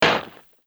SPADE_Dig_01_mono.wav